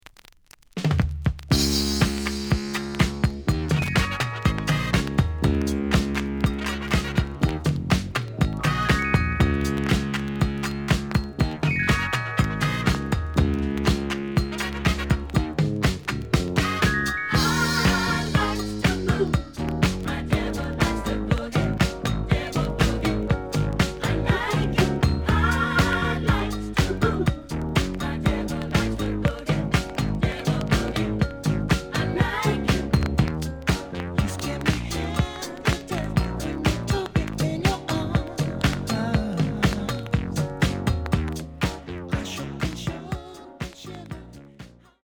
The audio sample is recorded from the actual item.
●Genre: Disco
Some click noise on both sides due to scratches.)